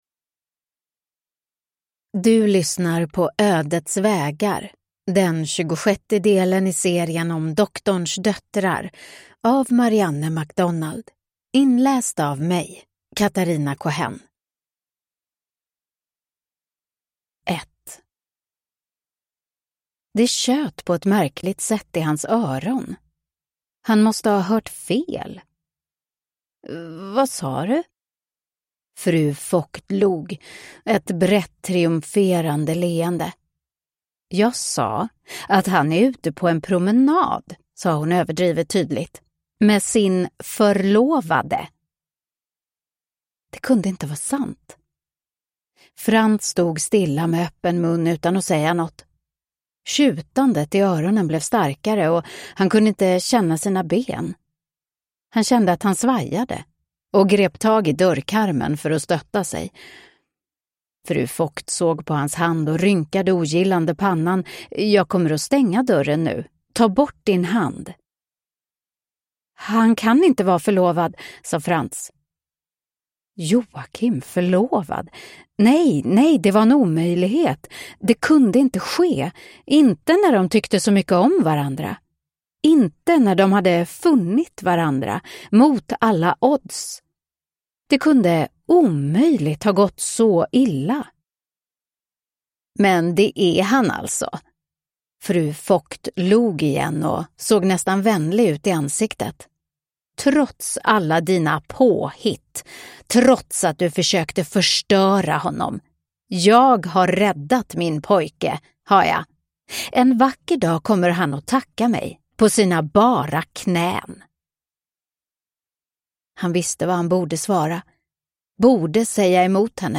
Ödets vägar (ljudbok) av Marianne MacDonald